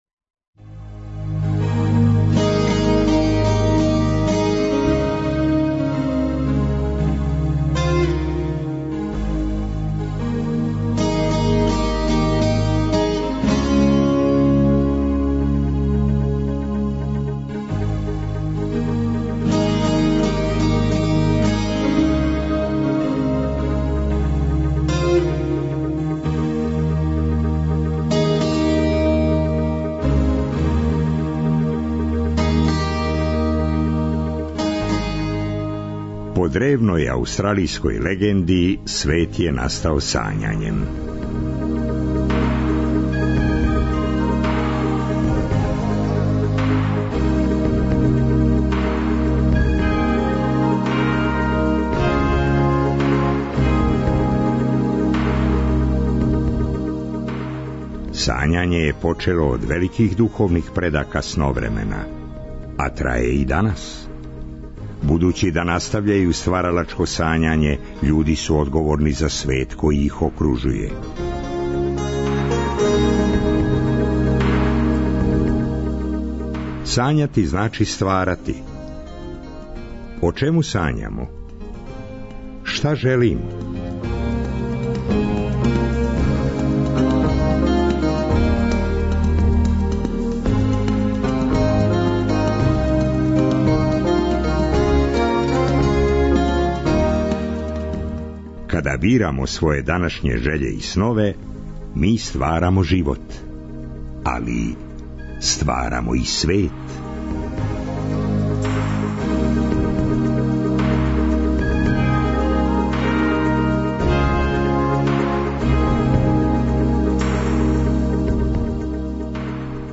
Зато нам је ноћас, у Сновремену, главни гост - гитара.